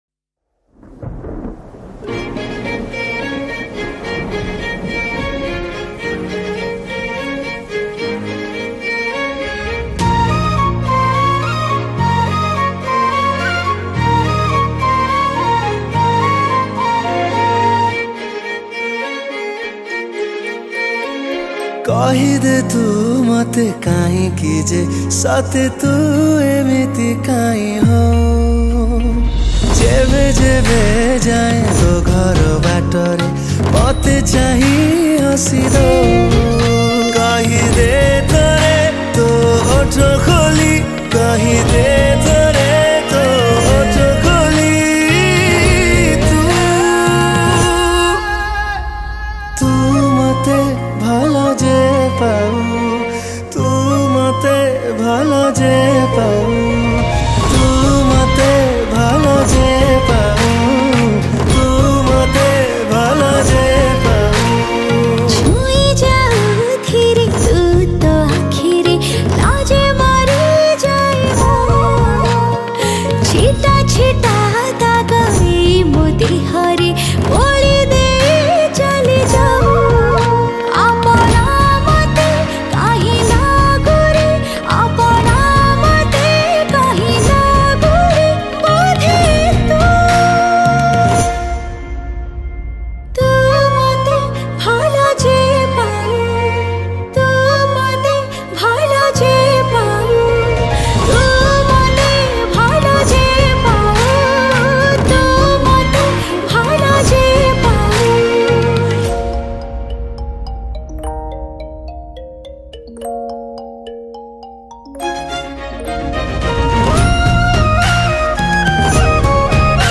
Flute
Violin